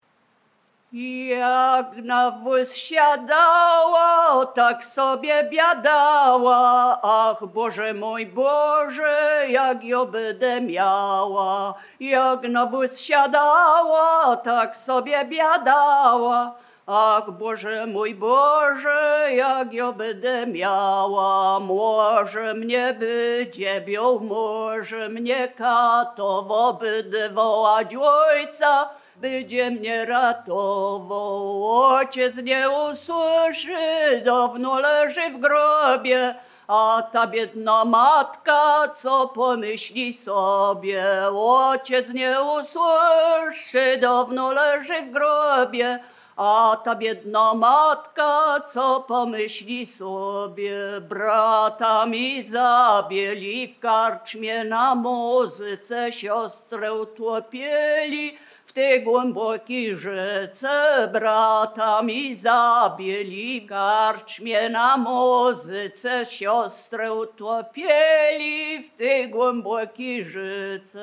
Biskupizna
Wielkopolska
województwo wielkopolskie, powiat gostyński, gmina Krobia, wieś Posadowo
Obyczajowa
Array liryczne obyczajowe